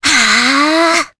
Cassandra-Vox_Casting4_jp.wav